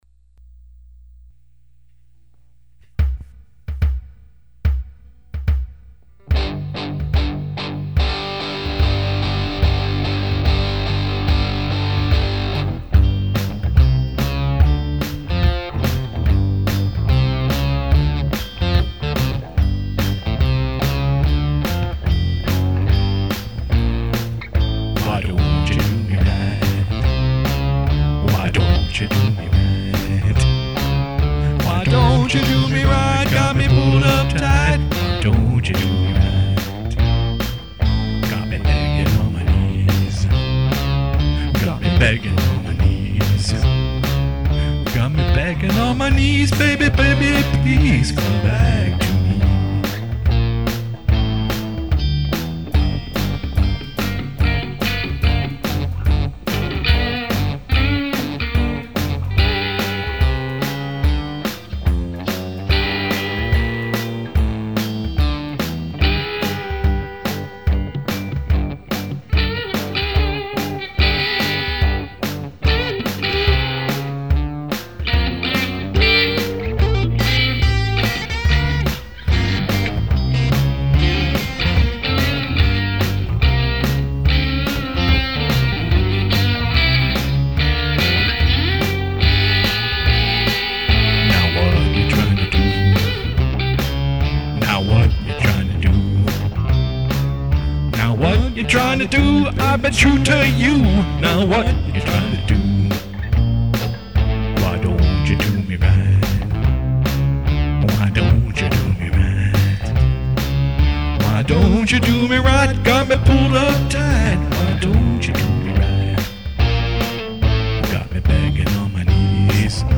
Gear: Roger Linn Adrenalinn III